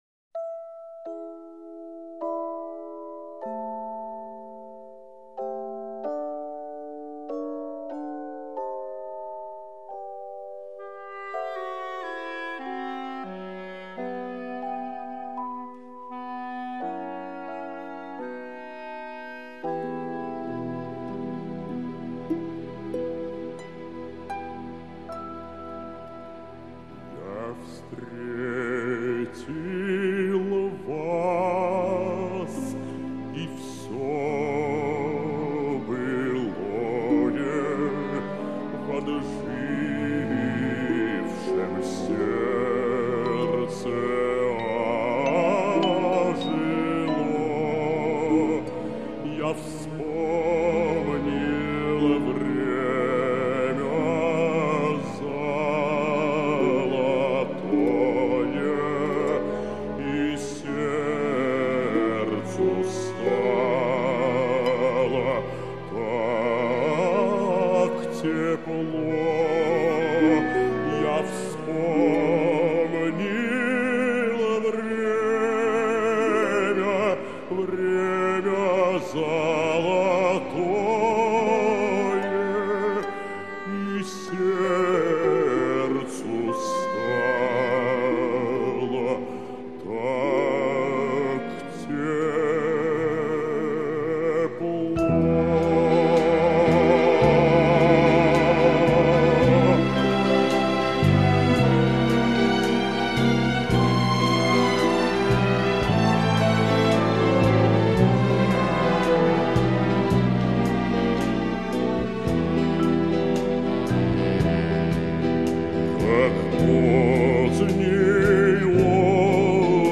Певцы